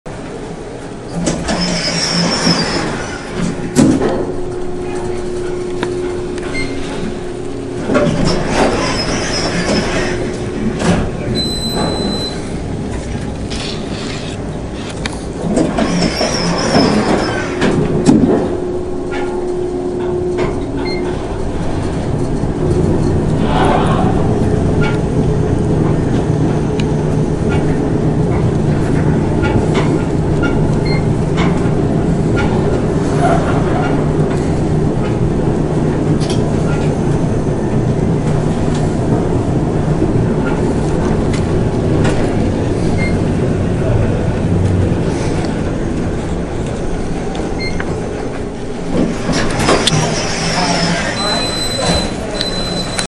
Elevator_long.mp3